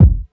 ILLMD006_KICK_MILLI_3.wav